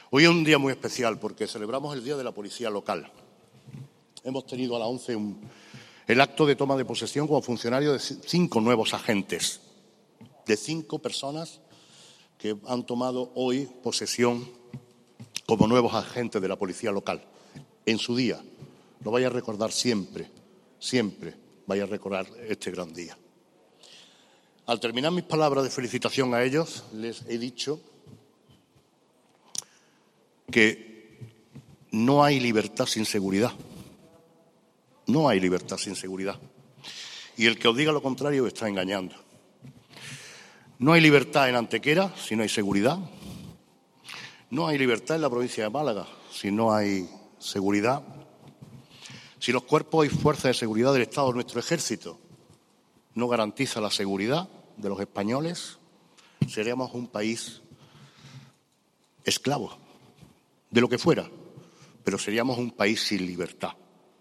"Sin seguridad, no hay libertad", manifestaba el Alcalde durante su discurso, quien confirmó que se seguirá apostando en mejorar el equipamiento de la Policía Local, agradeciendo la incuestionable e imprescindible labor realizada durante toda la pandemia del coronavirus, especialmente durante las semanas del confinamiento domiciliario.
Cortes de voz